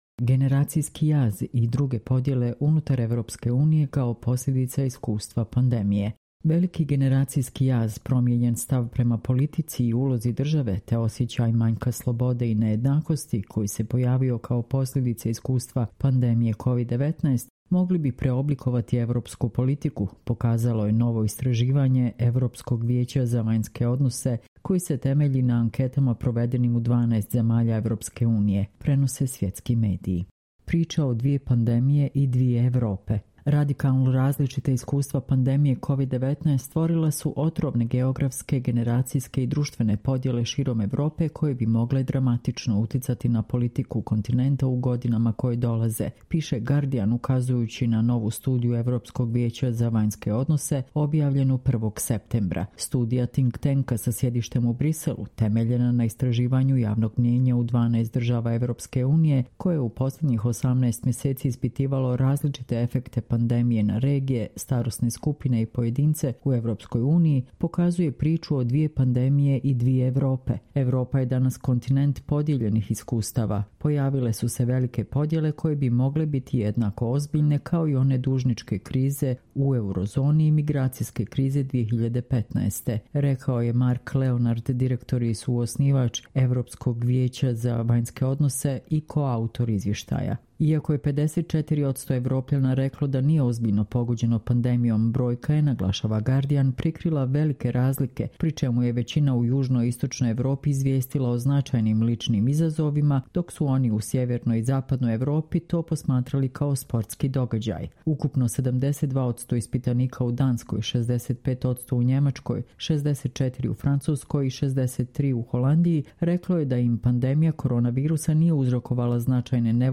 Čitamo vam: Generacijski jaz i druge podjele unutar EU kao posljedica iskustva pandemije